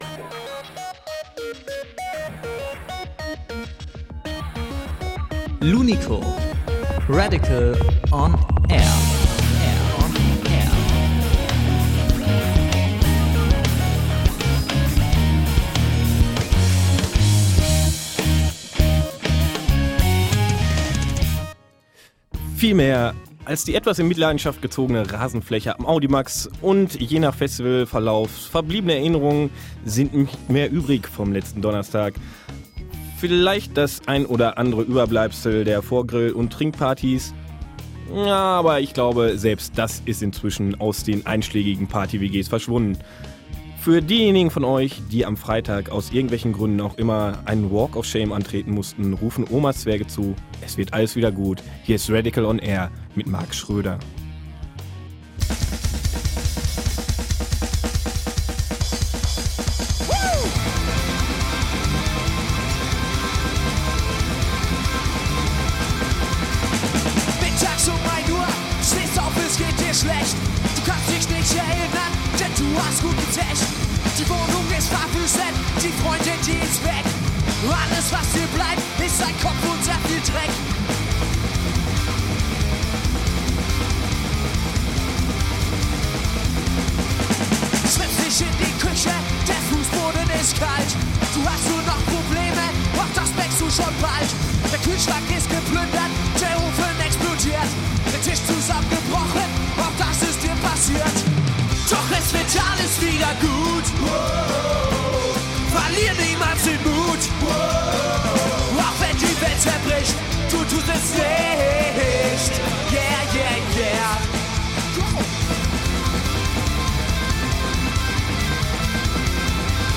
Live im Studio UNDERSTANDING MEDIA